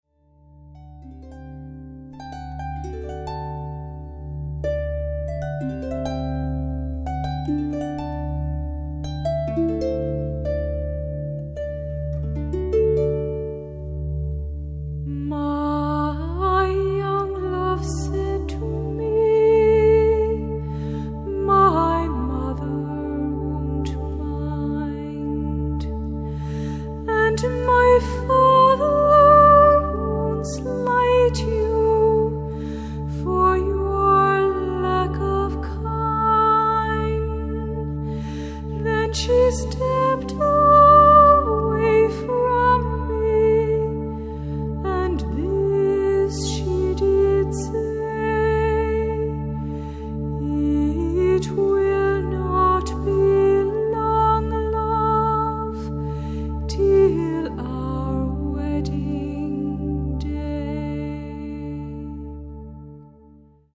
classic Irish songs and melodies